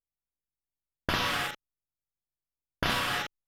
DISTO META-R.wav